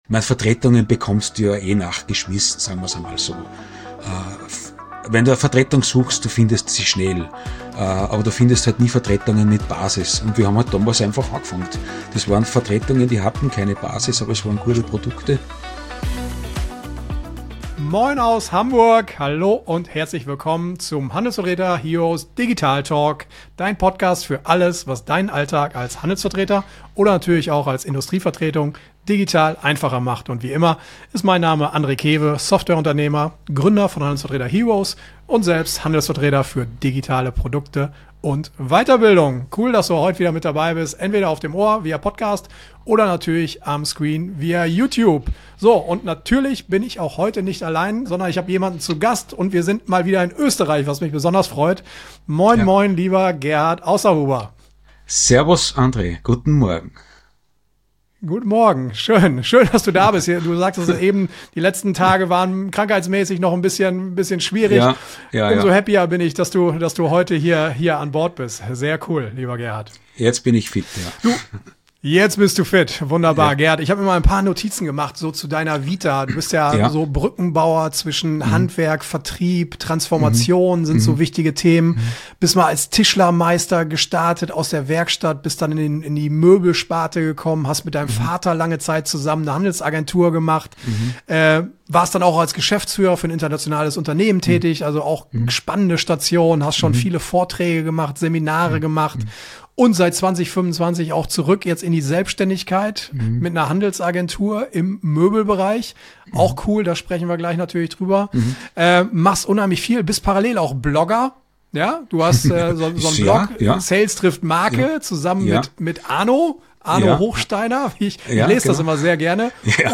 Ein Gespräch über Handwerk Vertrieb, Story statt Cent-Artikel, LinkedIn als Personal Brand und den pragmatischen Weg in die Digitalisierung.